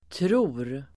Uttal: [tro:r]